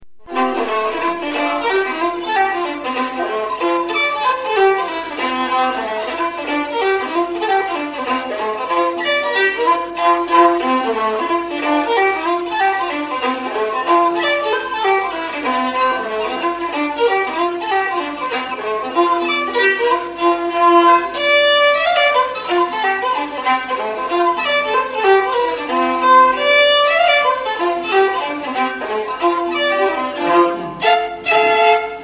Dance music of the south of England
Gloucestershire fiddler